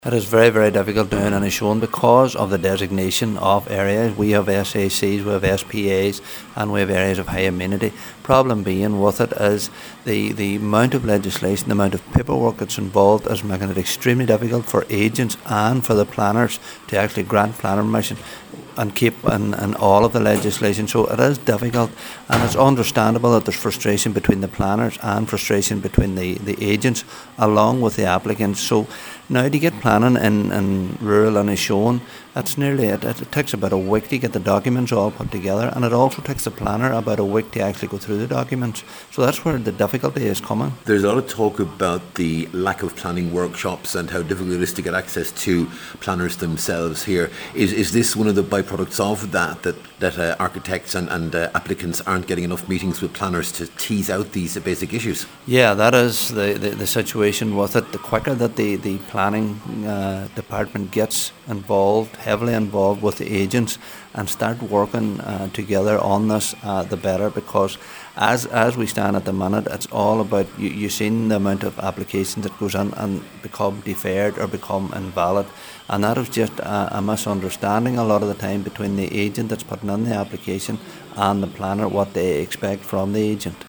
Cllr Canning says the problem is a significant one: